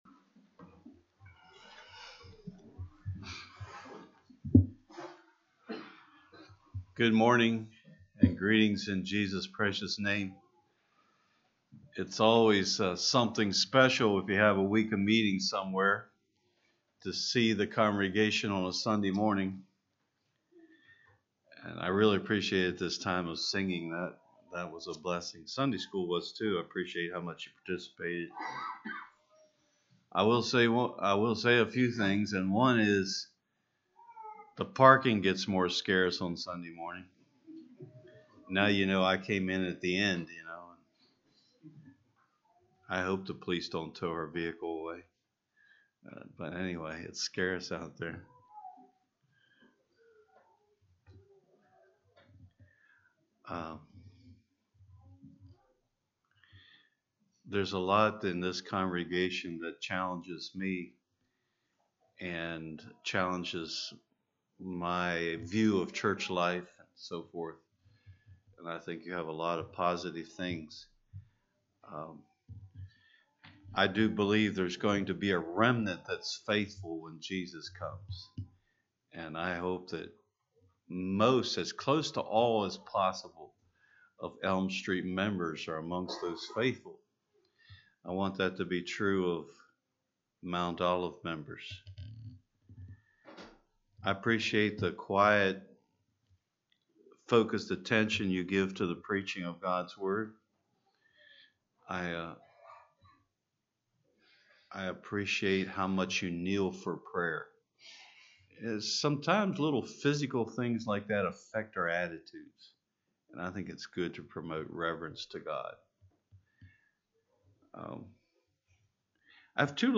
Evangelistic Meetings Categories